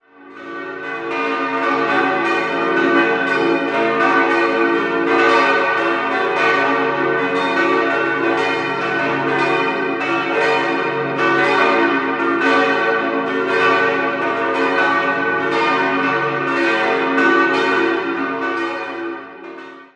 Im Jahr 1935 wurde die neue Kirchengemeinde gegründet und ein eigenes Gotteshaus errichtet, das im Frühjahr 1937 eingeweiht werden konnte. 5-stimmiges erweitertes B-Dur-Geläute: b°-d'-f'-g'-b' Alle Glocken wurden 1936 von der Gießerei Rüetschi in Aarau gegossen.